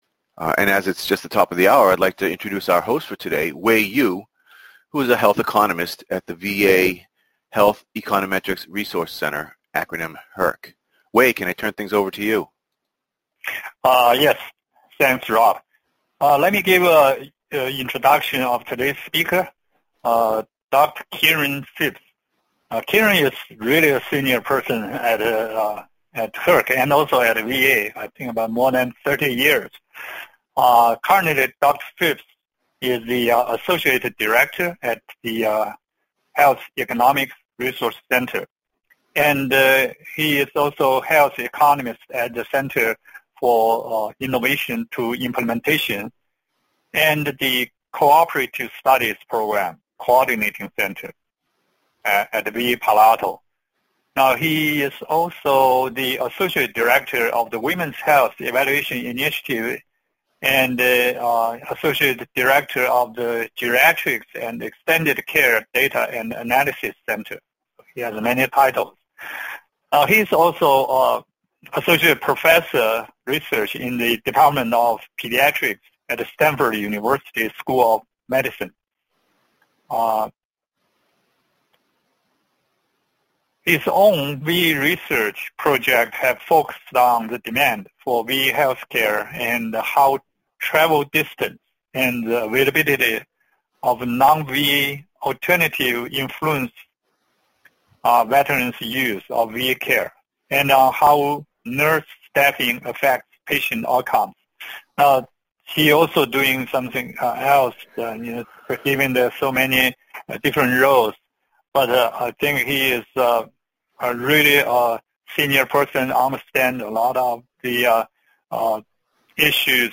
This lecture will address some of the common problems with right hand side variables, and introduce methods to test for them, and methods to correct these problems. Issues to be addressed include non-linearity and functional form, multicollinearity, clustering, and robust standard errors.